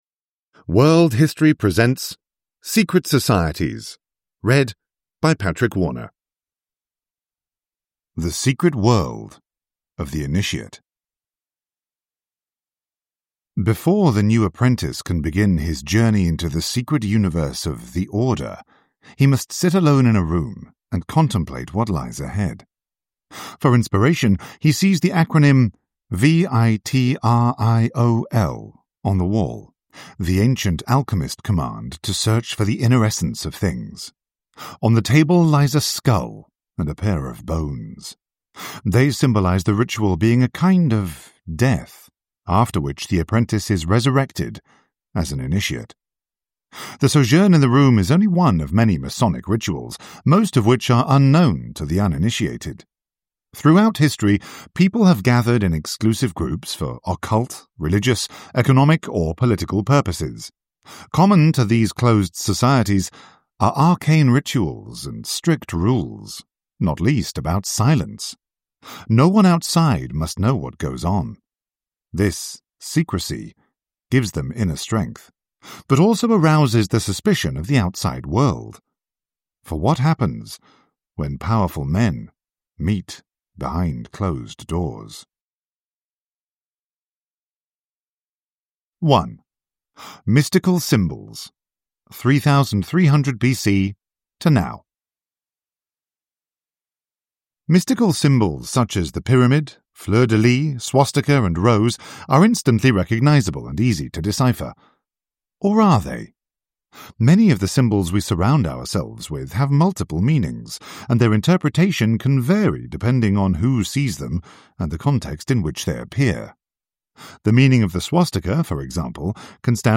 Secret Societies: the truth behind the world’s most powerful institutions – Ljudbok